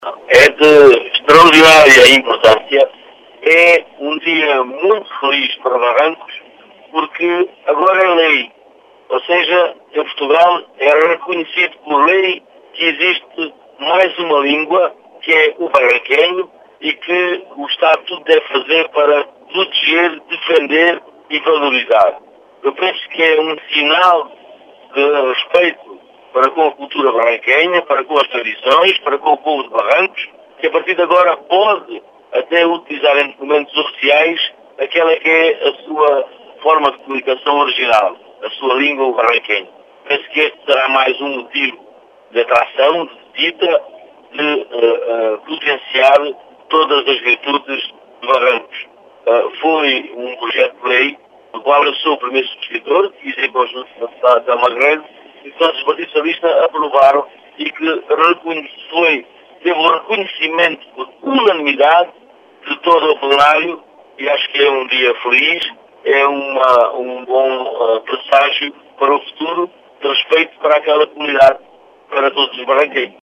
Em declarações à Rádio Vidigueira, o deputado socialista Pedro do Carmo diz ter sido “um dia muito feliz” para Barrancos, e de “extraordinária importância”.